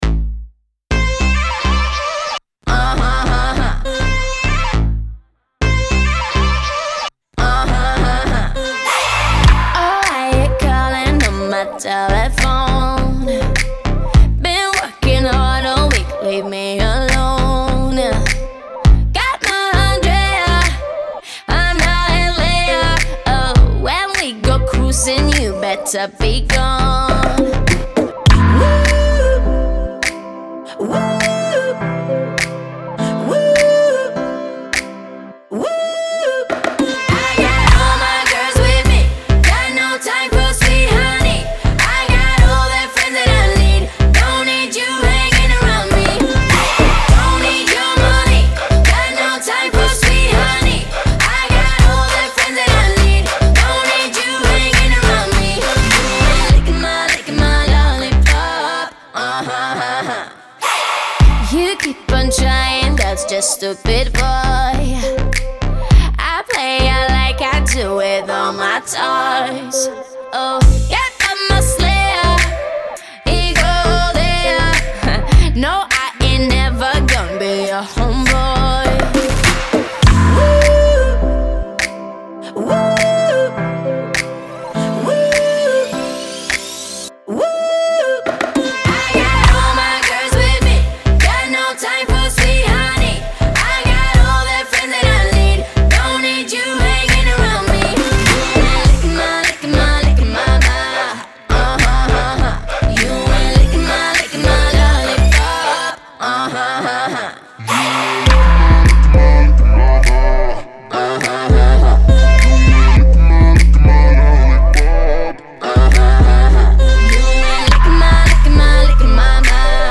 яркая и зажигательная поп-песня польской певицы